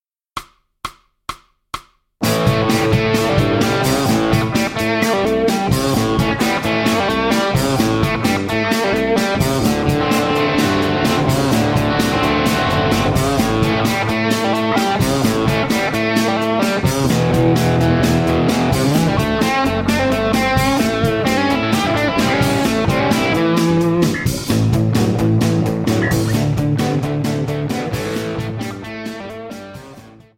Tablatures pour Guitare basse